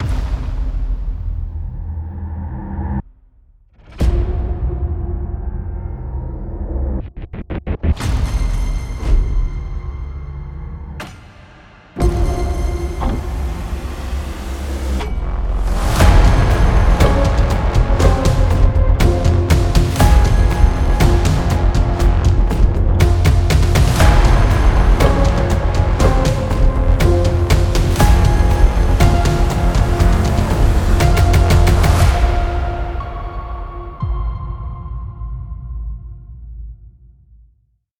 Genre: trailer, production.